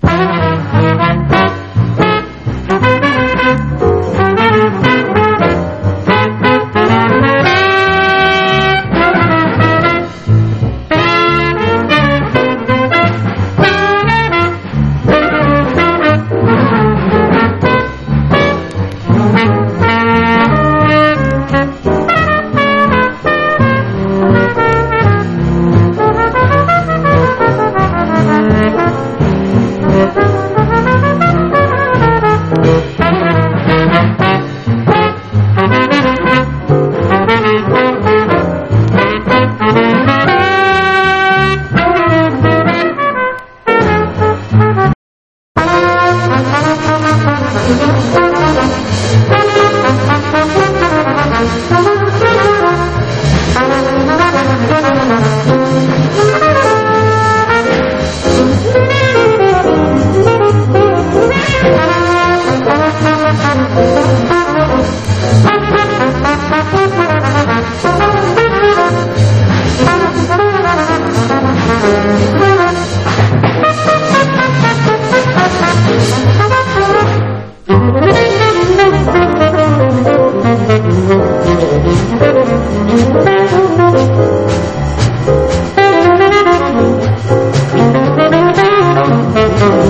激アツのオルガン・スウィング / R&Bジャズ！